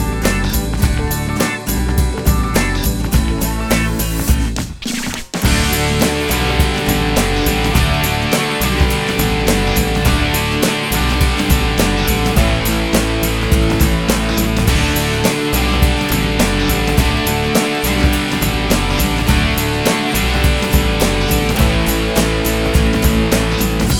no Backing Vocals Duets 3:36 Buy £1.50